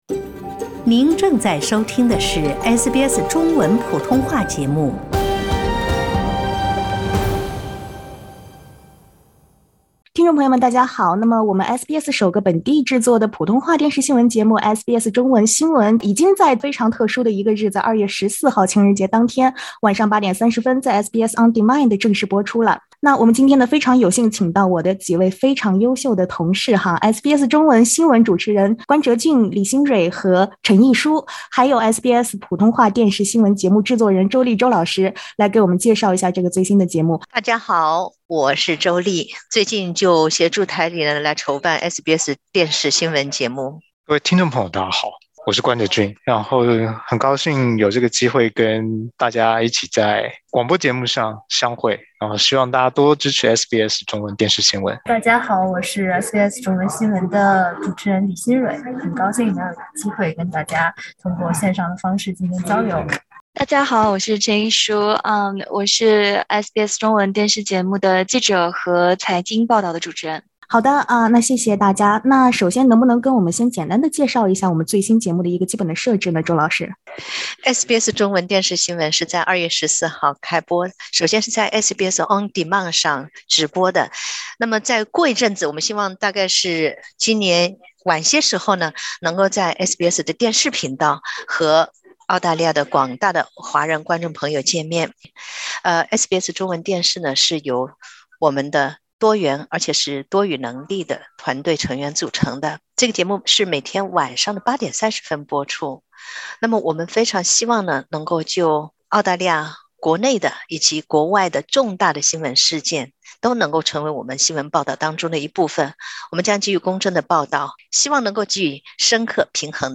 SBS推出全新普通话电视新闻SBS中文News，主创团队期望，能够成为华人融入澳洲的桥梁和窗口。（点击上图收听采访音频）